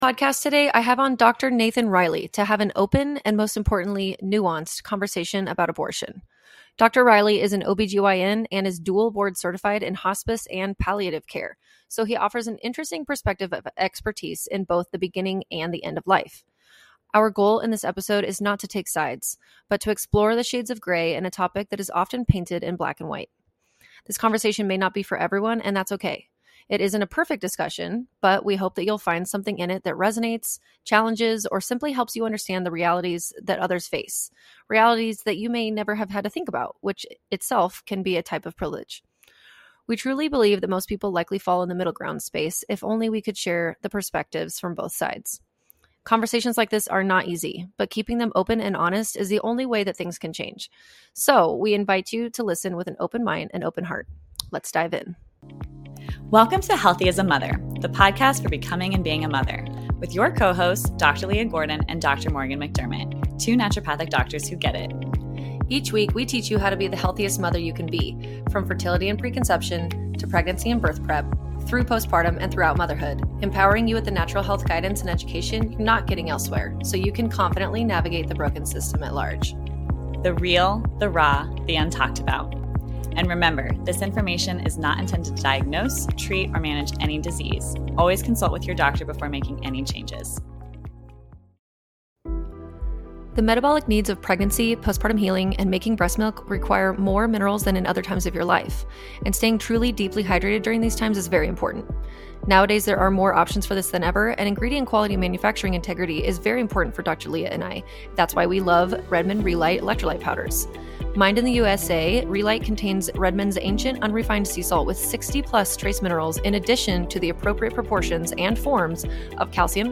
Conversations like this aren’t easy, but keeping them open and honest is the only way things can change.